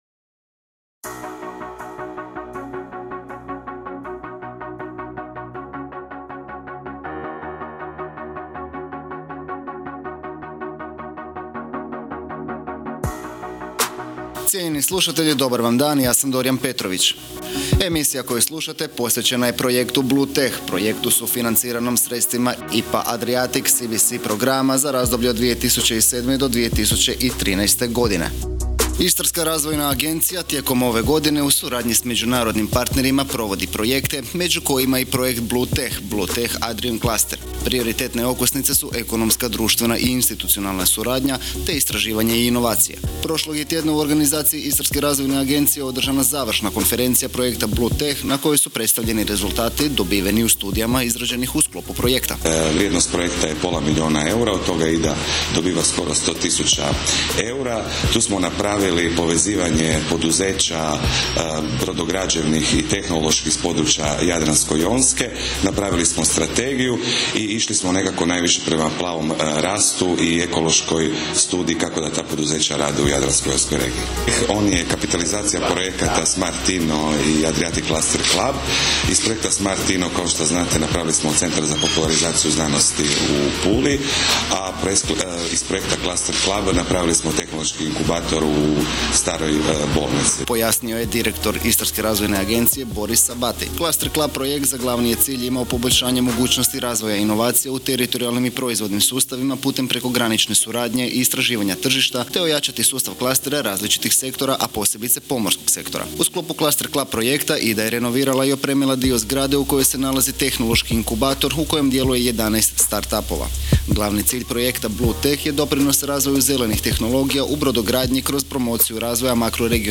Završna konferencija IDA-inog projekta BLUETECH